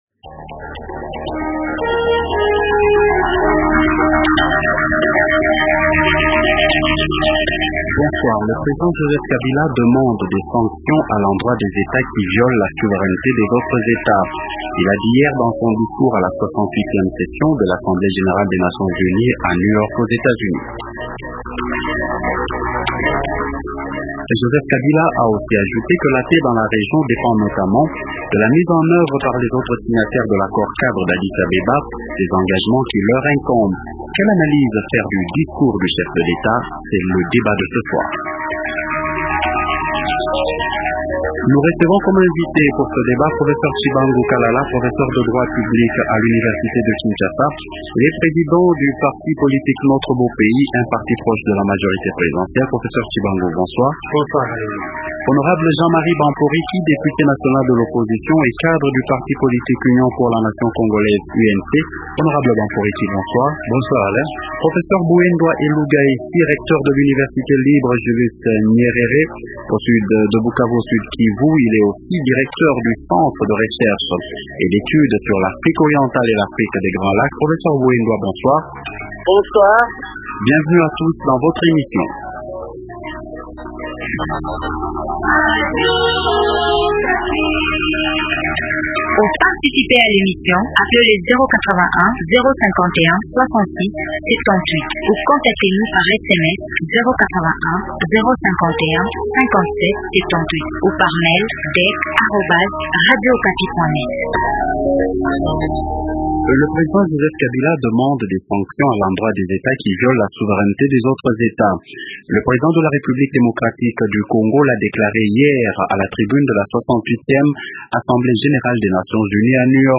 Jean-Marie Bamporiki, Député national de l’opposition et cadre du parti politique Union pour la nation congolais (Unc).